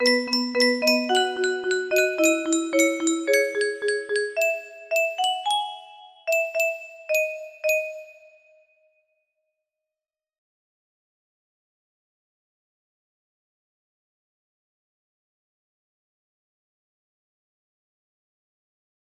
Okolo Frýdku cestička music box melody
Full range 60